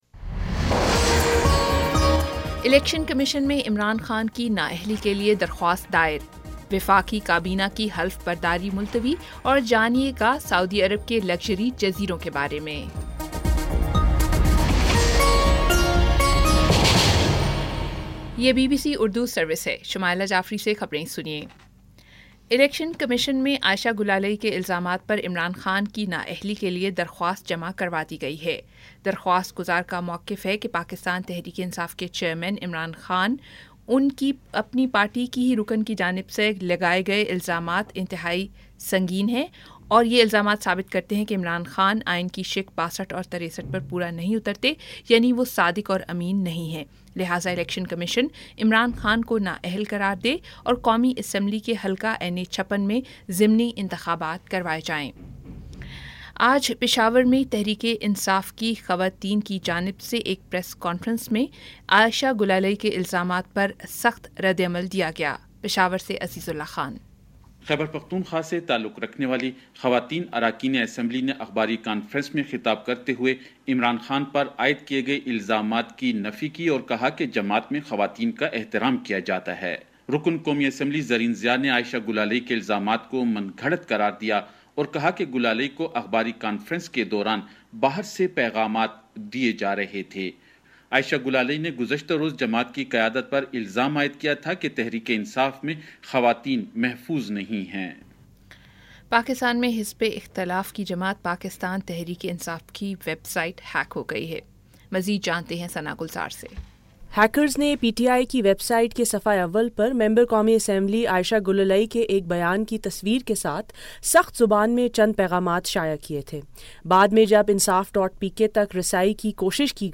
اگست 02 : شام پانچ بجے کا نیوز بُلیٹن